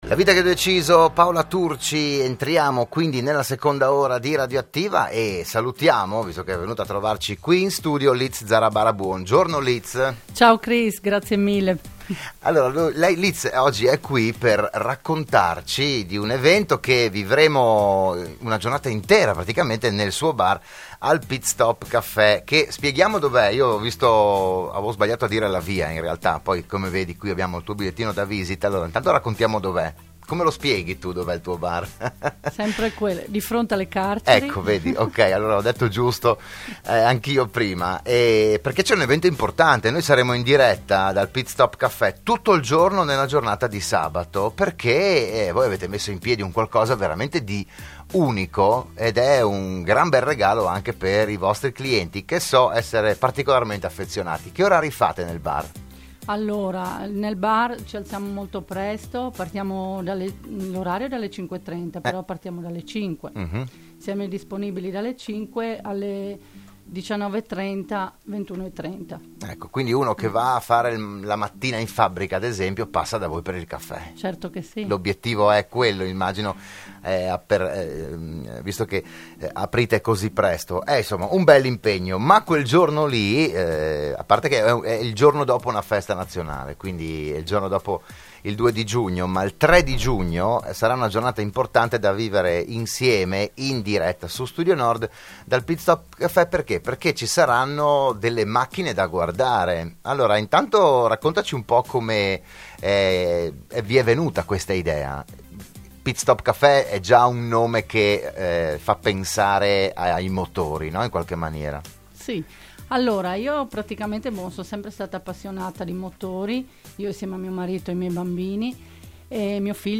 Dell’evento si è parlato a “ RadioAttiva “, la trasmissione di Radio Studio Nord